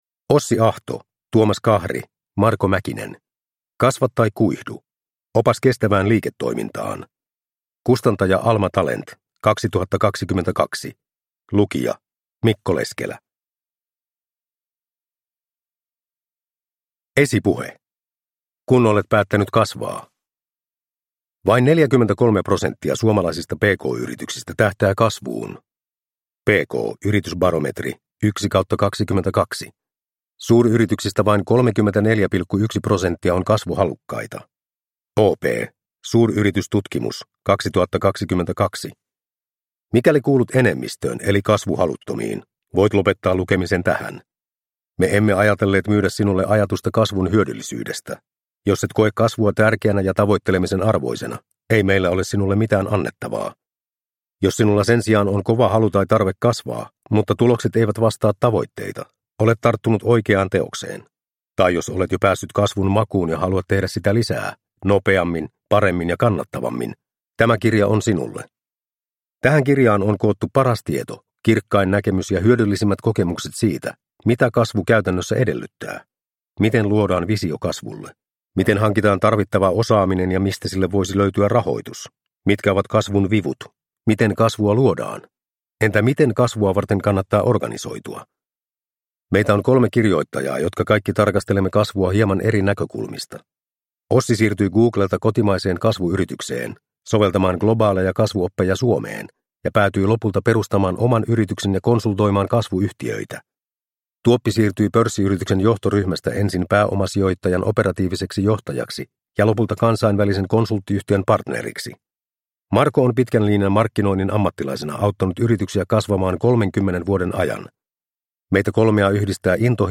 Kasva tai kuihdu – Ljudbok – Laddas ner
Produkttyp: Digitala böcker